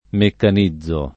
meccanizzare v.; meccanizzo [ mekkan &zz o ]